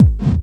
VEC3 Bassdrums Dirty 02.wav